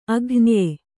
♪ aghnye